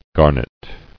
[gar·net]